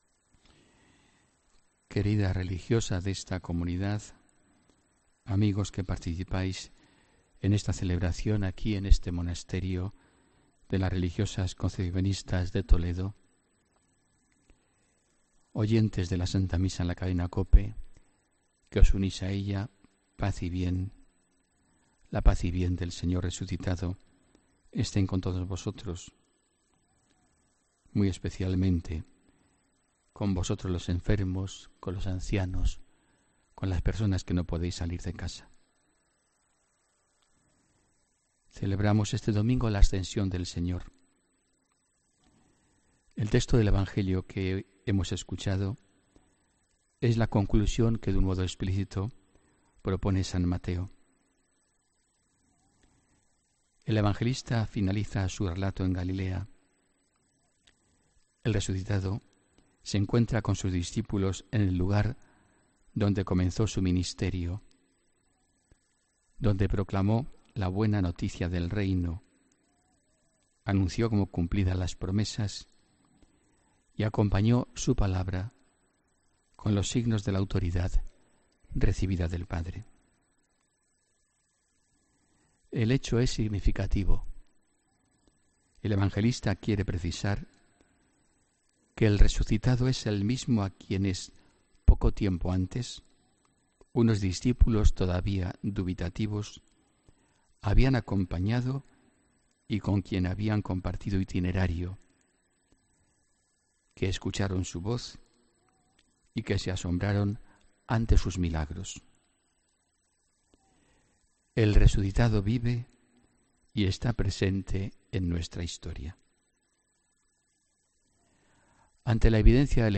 Homilía del domingo 28 de mayo de 2017